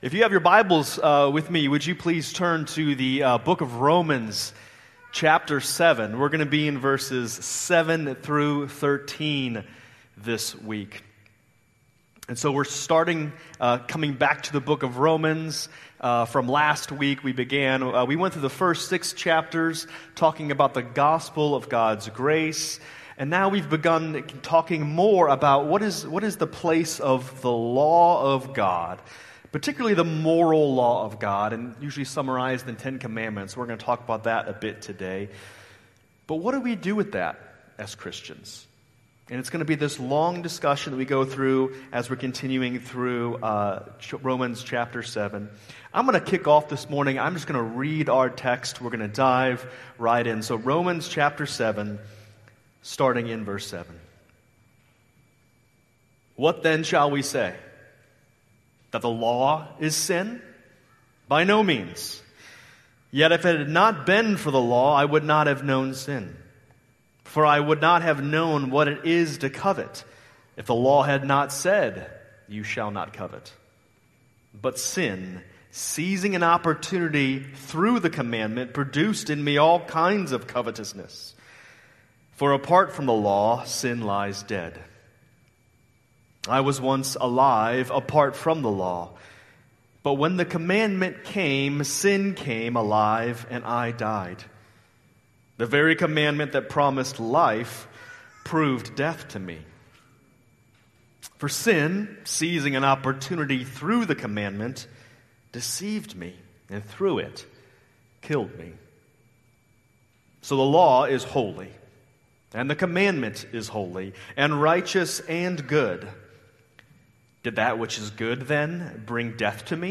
January 18, 2026 Worship Service Order of Service:
Sermon